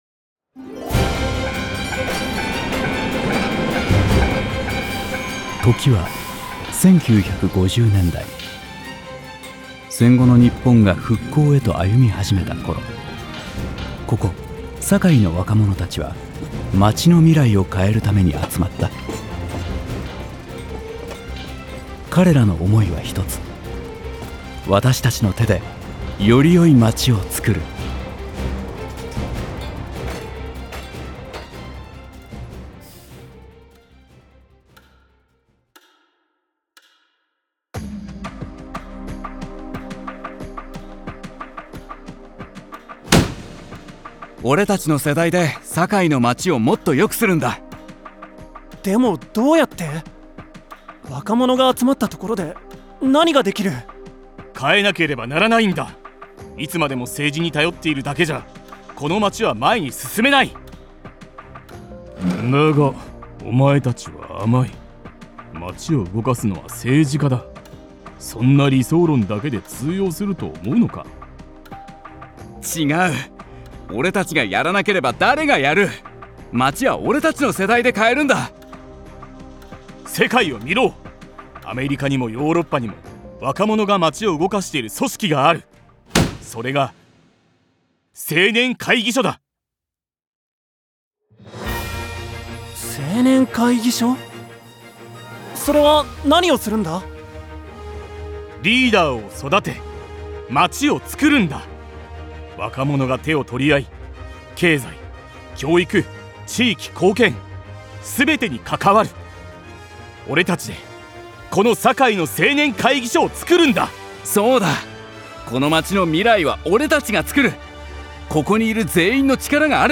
堺高石青年会議所様　70周年記念式典　ボイスドラマ
青年会議所設立の周年記念式典でのスピーチ前に上映するボイスドラマとして制作しました。
レコーディングには青年会議所の皆さんにもご参加いただきました。
路面電車、包丁鍛冶の金属が打ち合うような音を
路面電車の踏切の警告音は実際(現代)の阪堺電車のものを
加えて高度成長期の力強い成長のイメージでエンジン音や
豆腐屋のラッパなどのノスタルジックさを演出できそうな音も
時計の秒針や鐘の音を室内への場面の切り替えや、時間の経過として使用しています。
会議シーンの机を叩く音なども含めて、印象的な意味合いで
配置したく、残響をやや深めに追加しています。